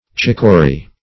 chiccory - definition of chiccory - synonyms, pronunciation, spelling from Free Dictionary Search Result for " chiccory" : The Collaborative International Dictionary of English v.0.48: Chiccory \Chic"co*ry\, n. See Chicory .
chiccory.mp3